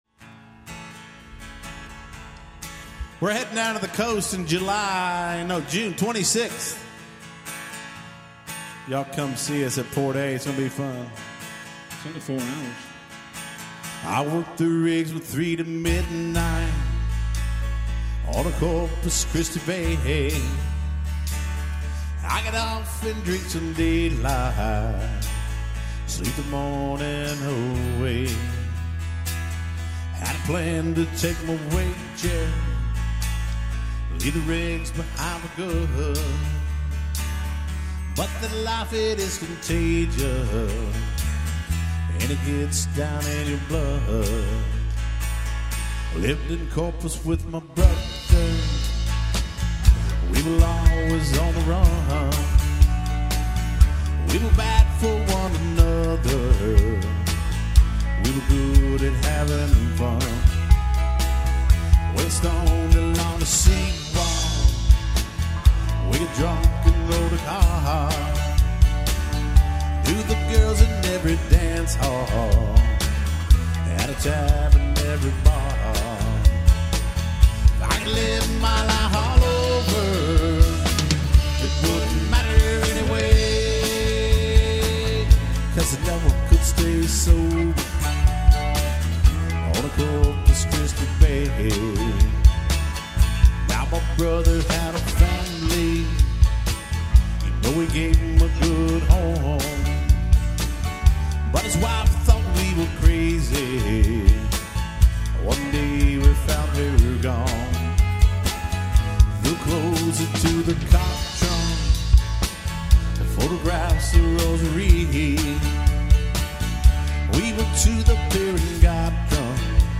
Here are a couple of songs we played at a recent wedding.
live, at Nutty Brown
classic Texas country music, plus a little rock and/or roll